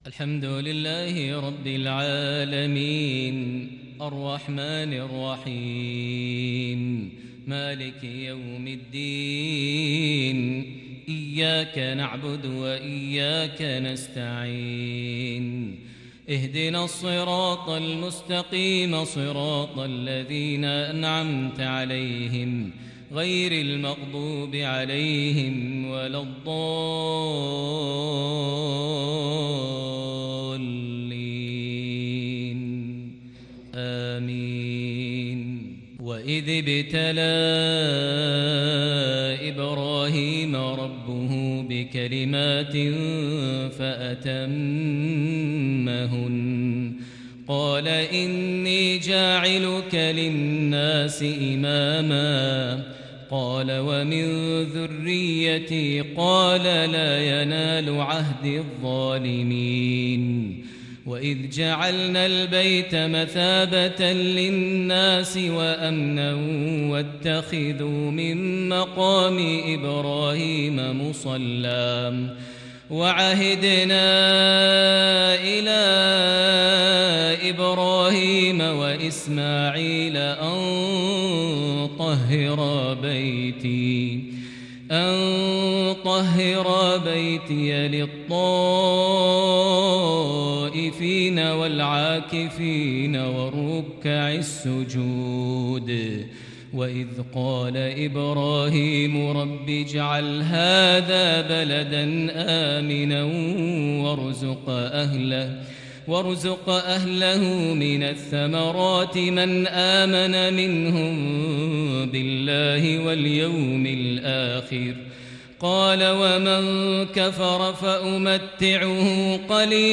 صلاة العشاء للشيخ ماهر المعيقلي 20 صفر 1442 هـ
تِلَاوَات الْحَرَمَيْن .